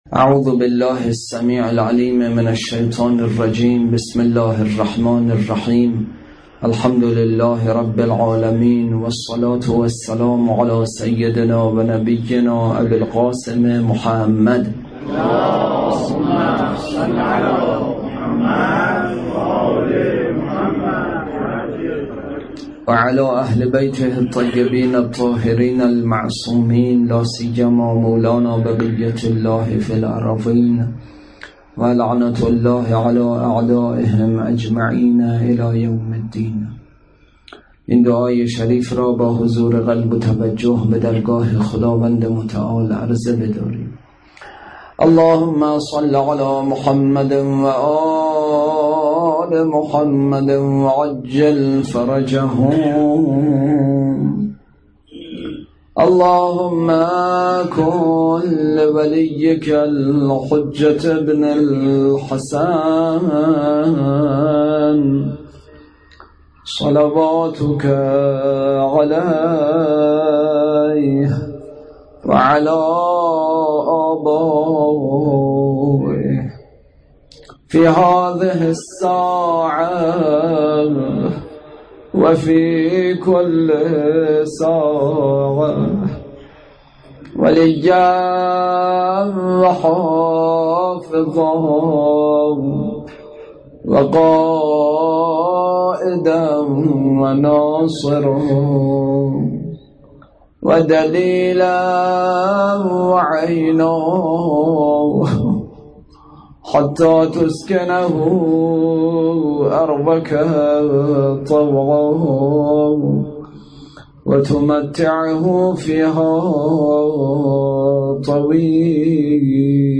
اطلاعات آلبوم سخنرانی
برگزارکننده: هیئت سفینه النجاه